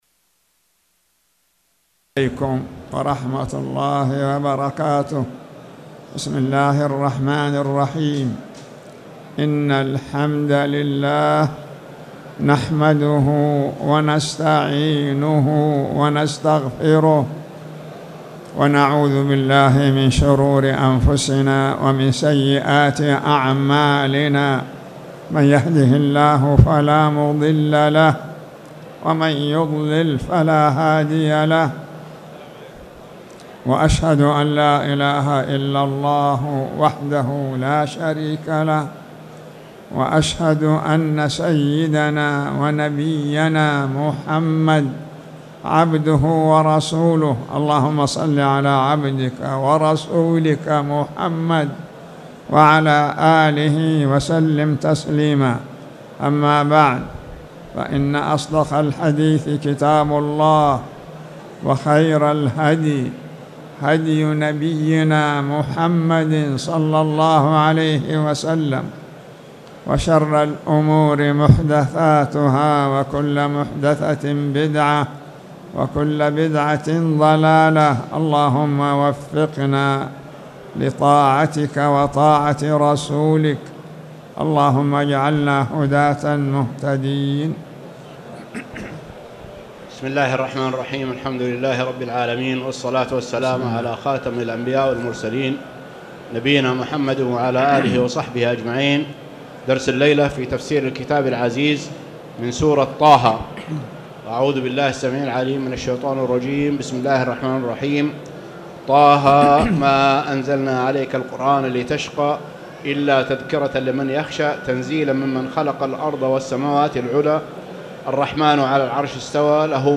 تاريخ النشر ٢٣ جمادى الأولى ١٤٣٨ هـ المكان: المسجد الحرام الشيخ